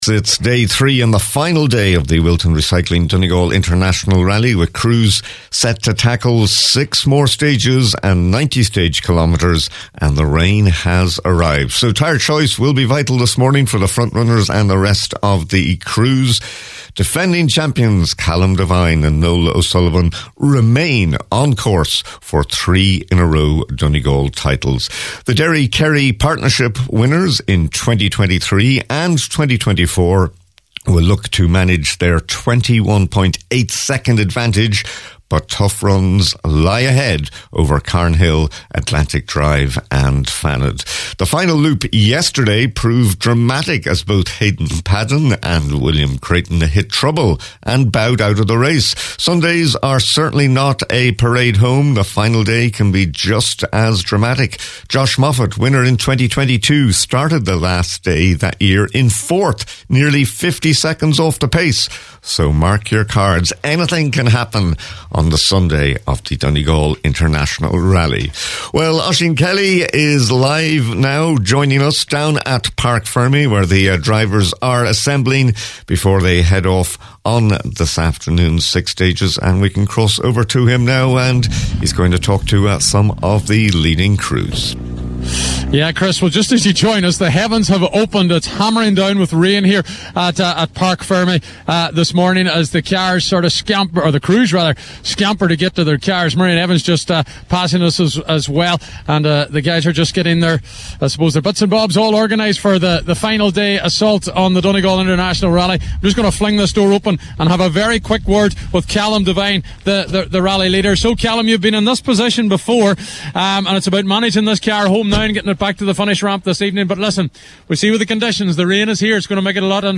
spoke to some of the top competitors